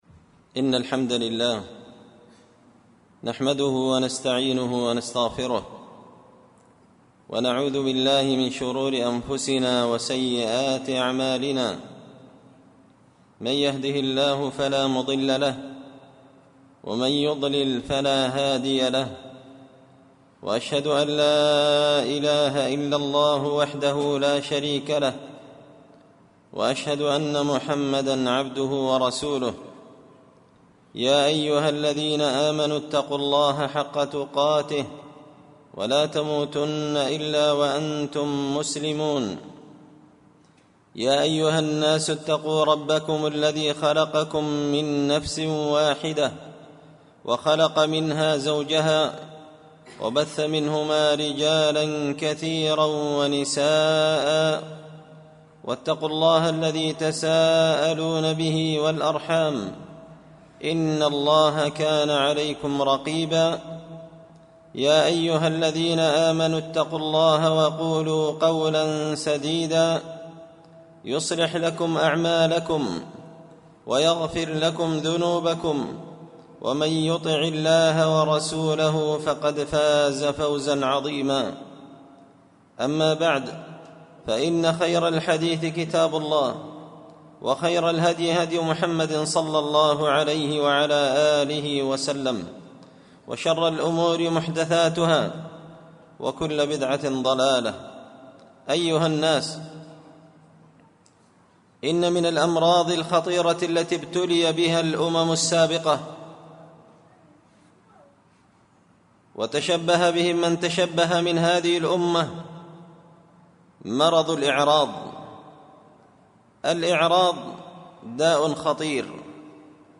خطبة جمعة بعنوان – الإعراض أنواعه وعواقبه
دار الحديث بمسجد الفرقان ـ قشن ـ المهرة ـ اليمن